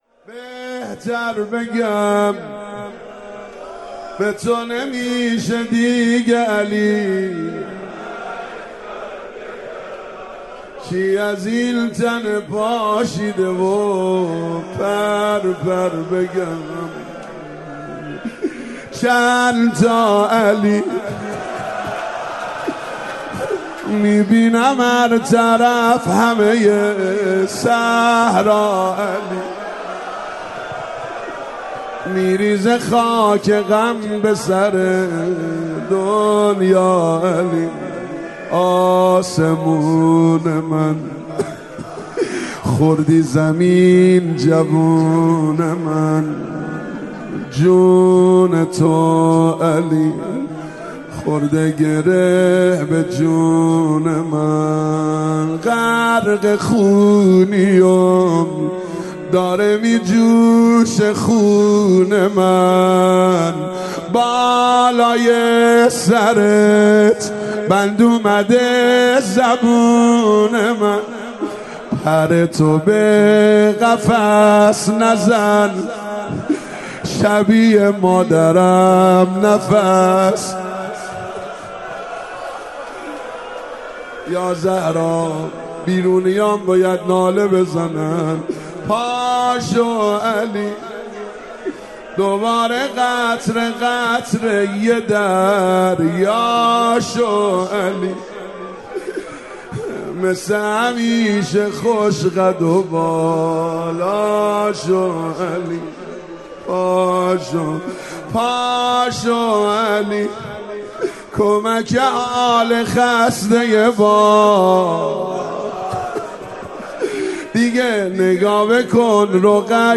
• شعر, روضه